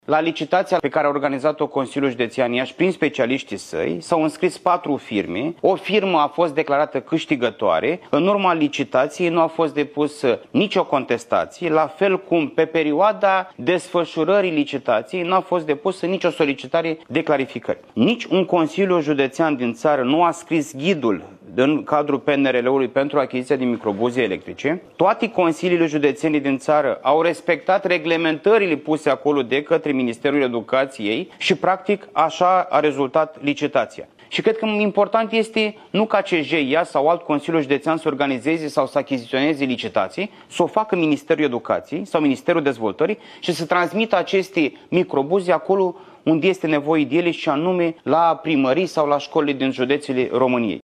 Într-o primă reacție, președintele Consiliului Județean Iași, Costel Alexe afirmă că achiziția s-a făcut cu respectarea legii și a ghidurilor de finanțare.